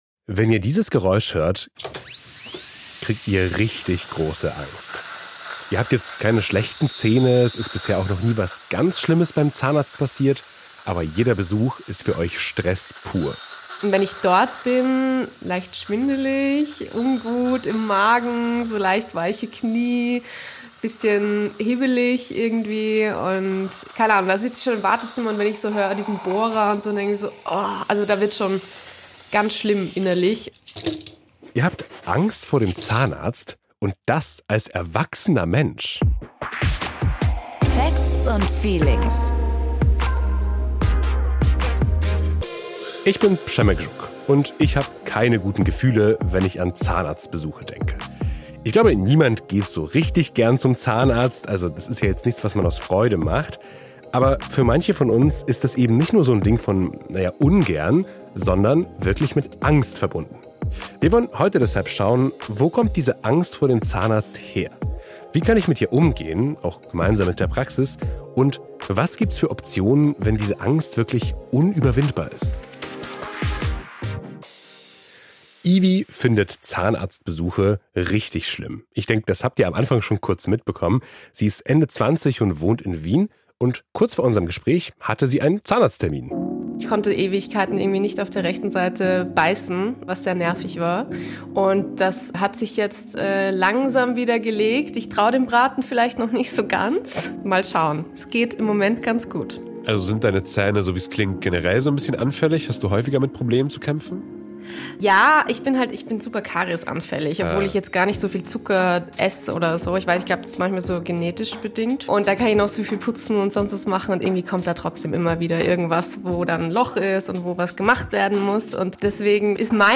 Konfrontationstraining kann uns helfen, die Angst zu überwinden, sagt eine Psychologin. Ein Zahnarzt erzählt, welche Hilfestellungen es gibt und welche Wünsche er seinen Angstpatienten erfüllt.